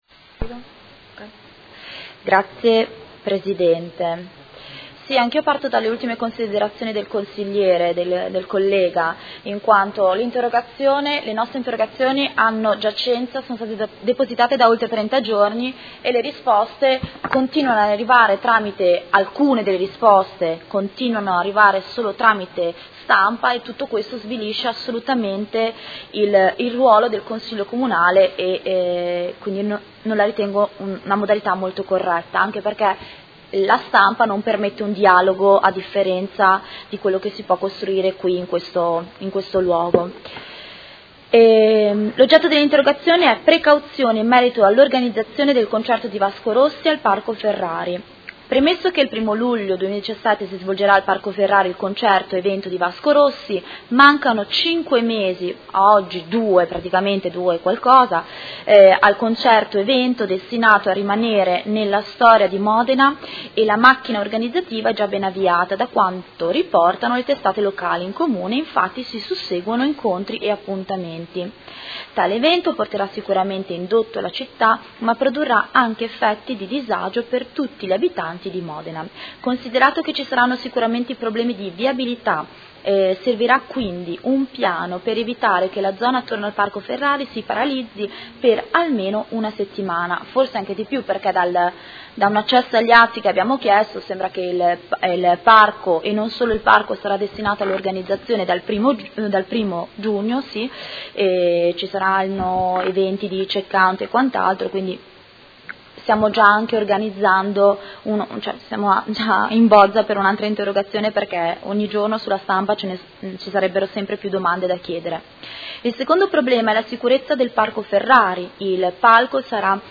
Seduta del 30/03/2017. Interrogazione del Gruppo Movimento cinque Stelle avente per oggetto: Precauzioni in merito all’organizzazione del concerto di Vasco Rossi al Parco Ferrari